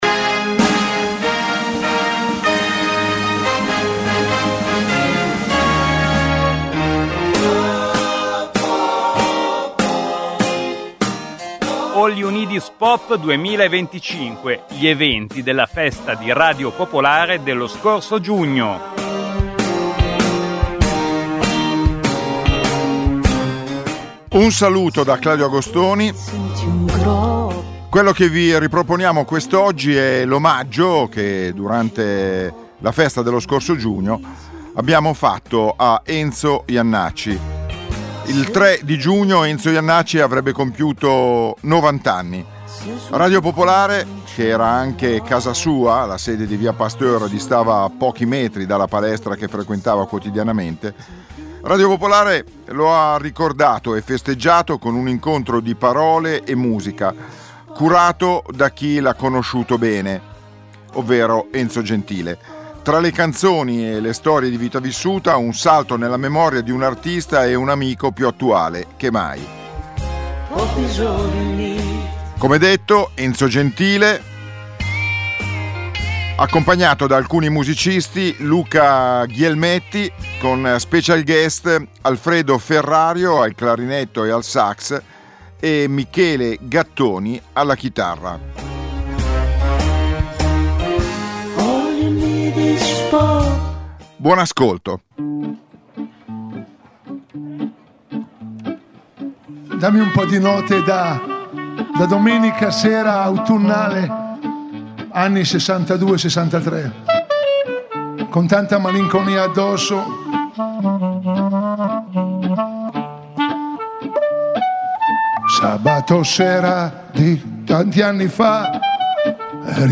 Radio Popolare – che era anche casa sua: la sede di via Pasteur distava pochi metri dalla palestra che frequentava quotidianamente – lo ricorda e festeggia con un incontro di parole e musica, curato da chi l’ha conosciuto bene. Tra le canzoni e le storie di vita vissuta, un salto nella memoria di un artista e amico più attuale che mai.
clarinetto e sax
chitarra). Il meglio della festa di Radio Popolare, All You Need Is Pop del 6, 7 e 8 giugno 2025